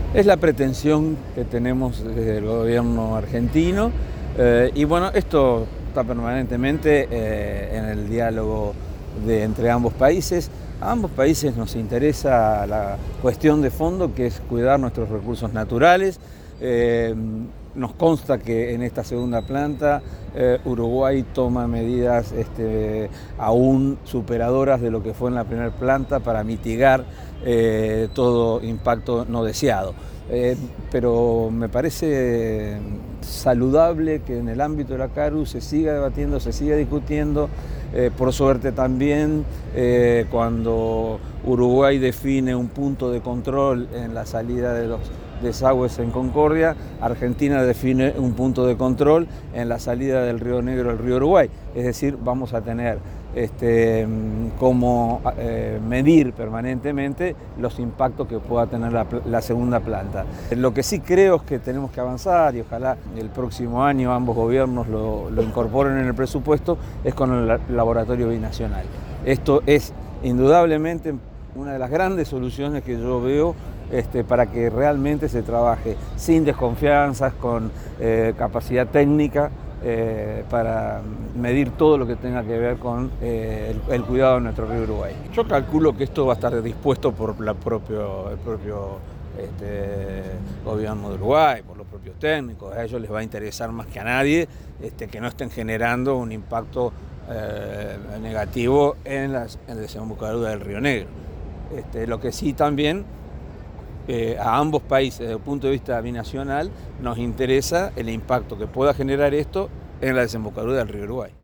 El embajador de Argentina en Uruguay, Mario Barletta dijo que “nos consta que Uruguay toma medidas aún superadores de lo que fue en la primera planta para mitigar todo impacto no deseado”.
Las declaraciones se realizaron en la celebración del Aniversario 209 de la revolución de Mayo acto en la Plaza Soldados Orientales de San Martín sobre Avenida Agraciada y Grito se Asencio.